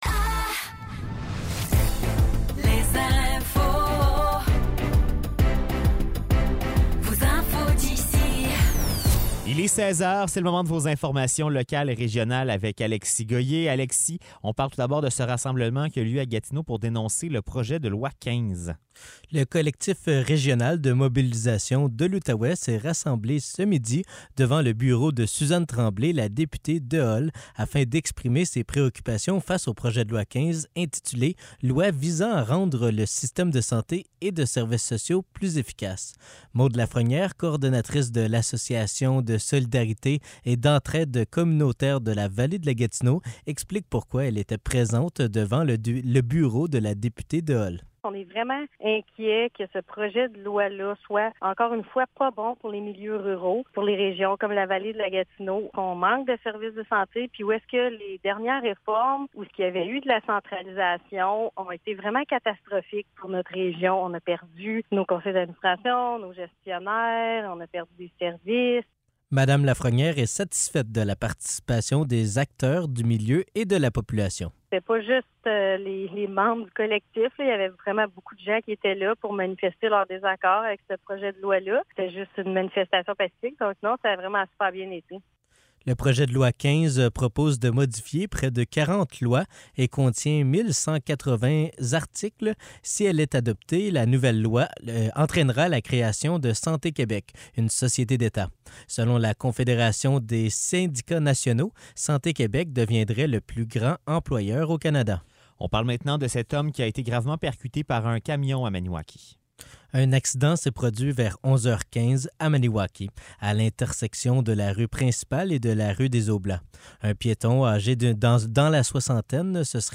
Nouvelles locales - 14 novembre 2023 - 16 h